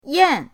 yan4.mp3